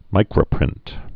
(mīkrə-prĭnt)